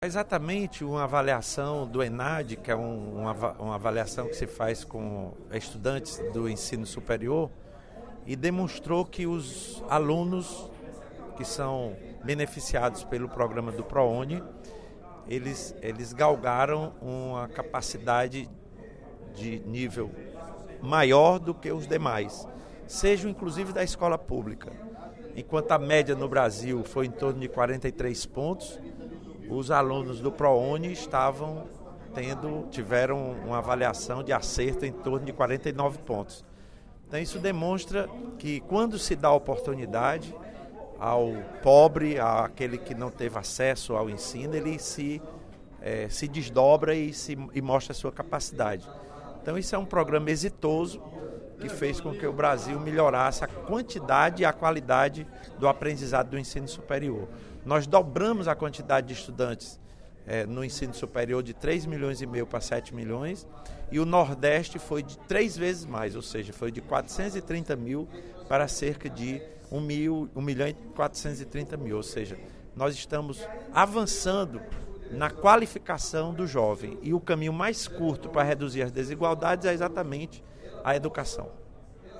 Durante o primeiro expediente da sessão plenária desta quarta-feira (05/11), o deputado Lula Morais (PCdoB) comemorou os avanços educacionais dos estudantes contemplados com programas do Governo Federal.